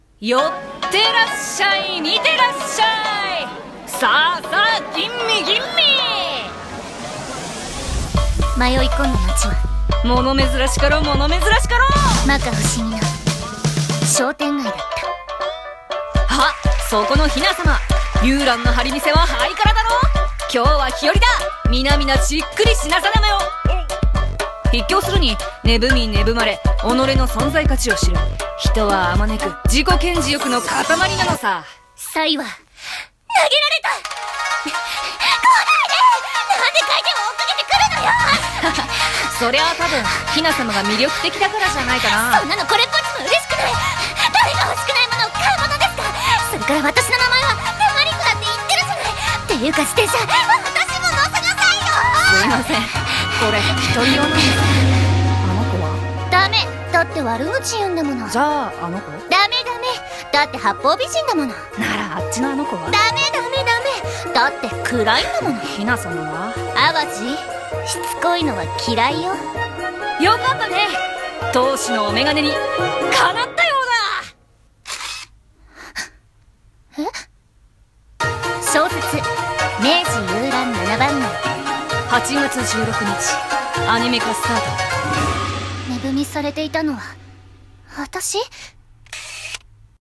CM風声劇「明治ゆうらん七番街」お手本